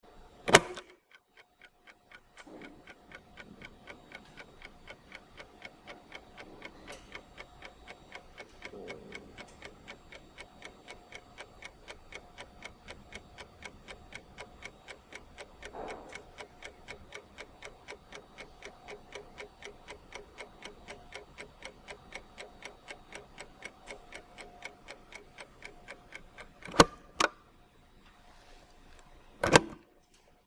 Big Stopwatch Sound Buttons
big-stopwatch-75605.mp3